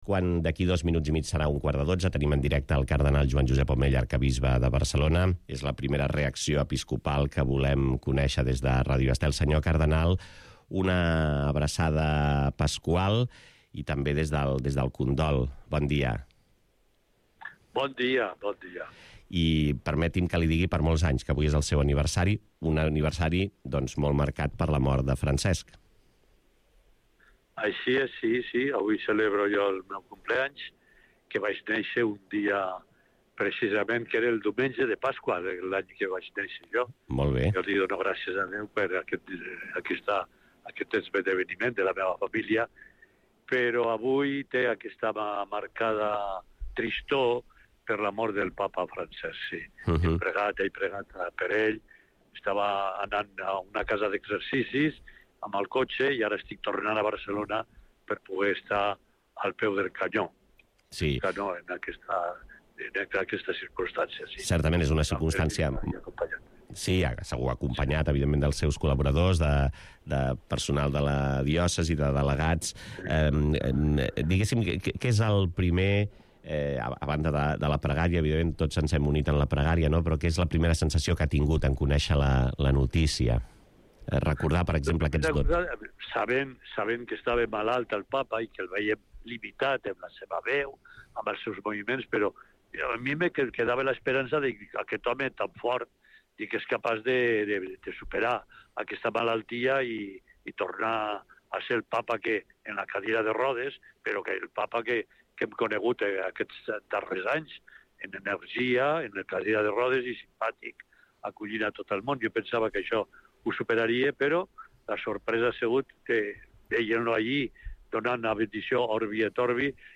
Entrevista a l'Arquebisbe de Barcelona, el cardenal Joan Josep Omella